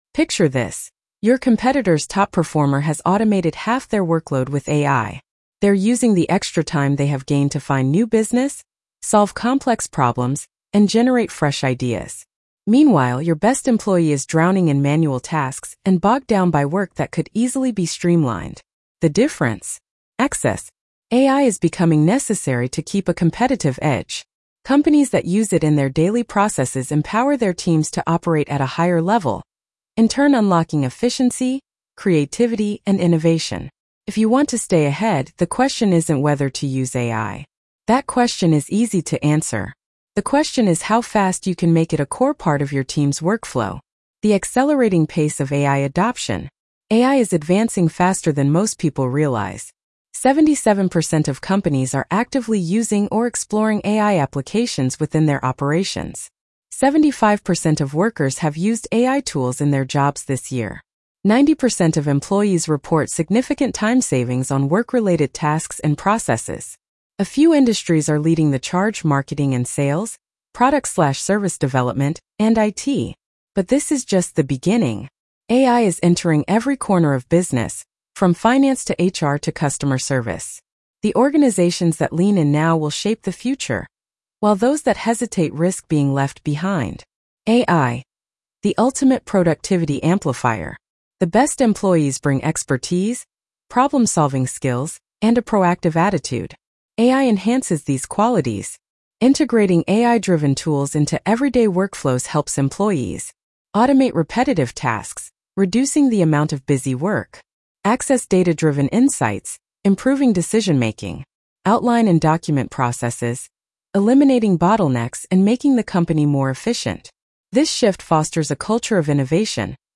How AI Can Supercharge Your Top Talent Blog Narration.mp3